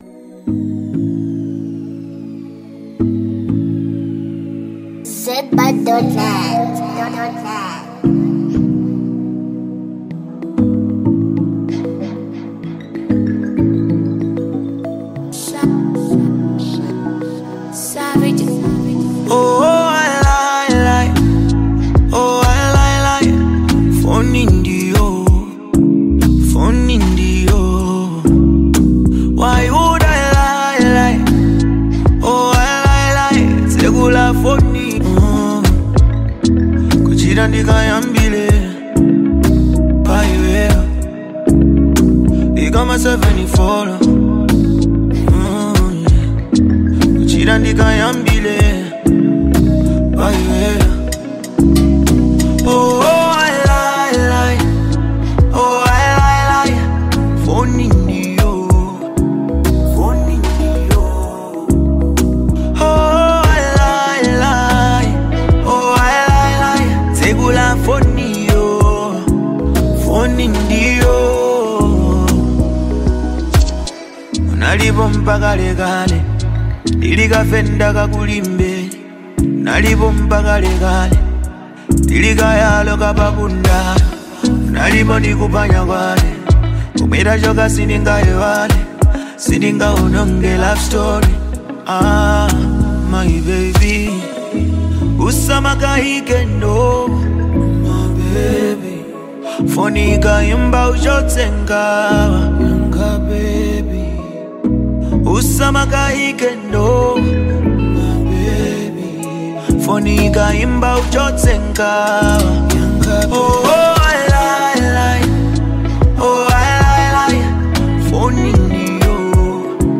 with its harmonious layers and infectious rhythm.